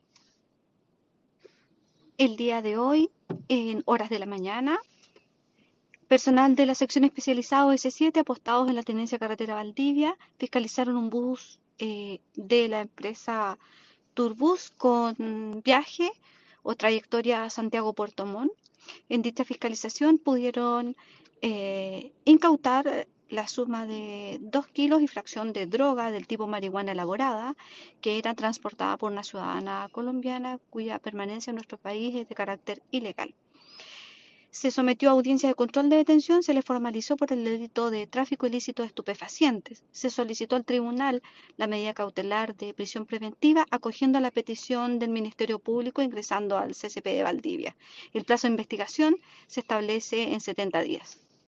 En tanto la fiscal subrogante Paola Riquelme sobre la formalización efectuada esta tarde por la Fiscalía de La Unión a una mujer de nacionalidad colombiana como autora del delito de tráfico ilícito de drogas indica que la imputada quedó en prisión preventiva.